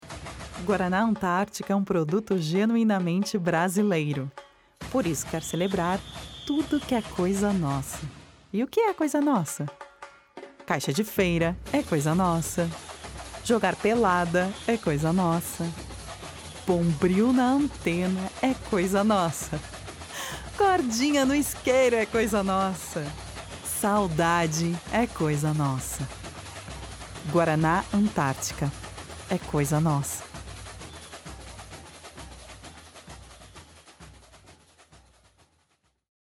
Feminino
Comercial energético
Voz Padrão - Grave 00:36